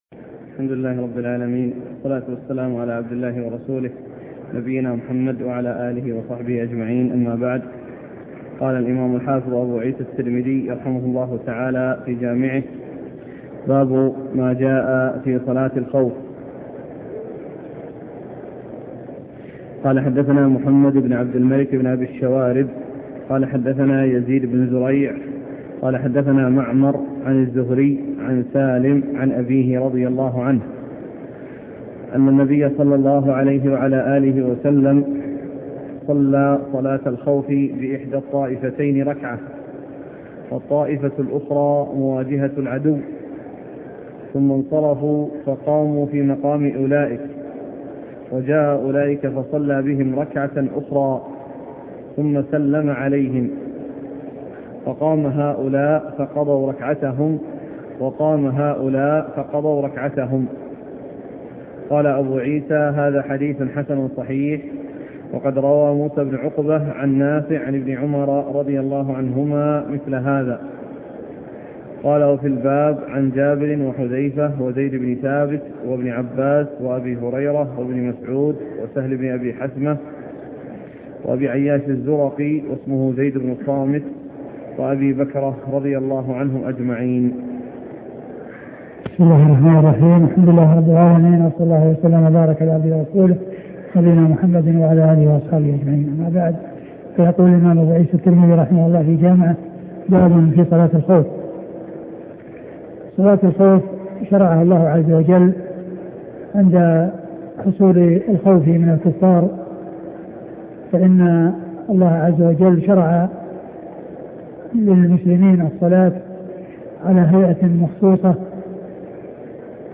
سنن الترمذي شرح الشيخ عبد المحسن بن حمد العباد الدرس 79